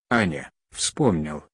Ну просто показал, как работает бот.